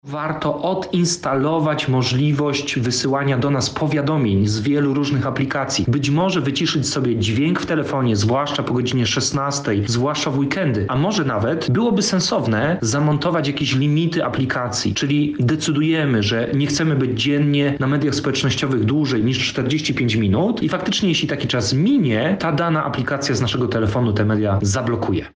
opowiada trener mentalny